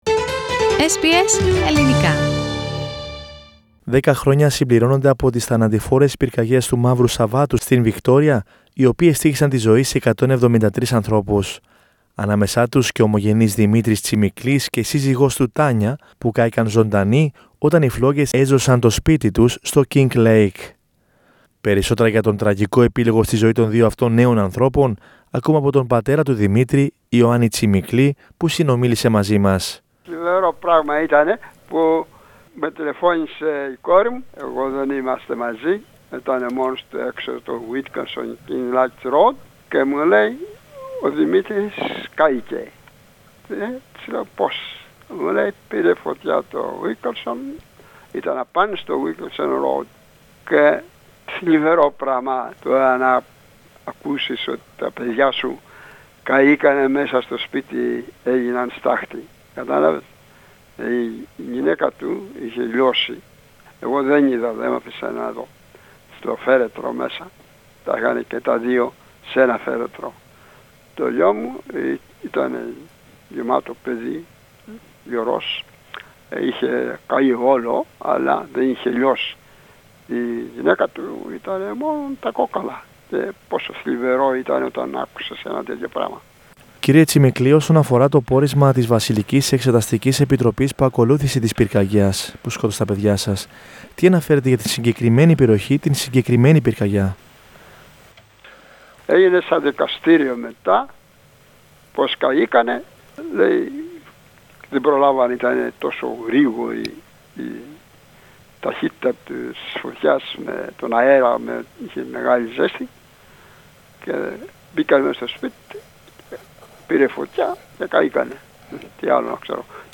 Η μαρτυρία ομογενή που έχασε τα παιδιά του στις πυρκαγιές του «Μαύρου Σαββάτου» στην Βικτώρια.